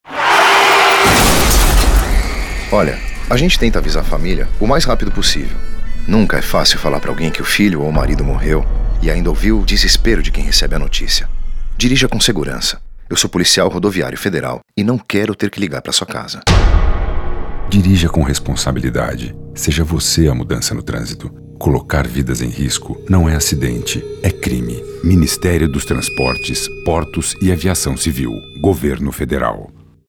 Spot de rádio
ministerio-dos-transportes-spot-2106-mp3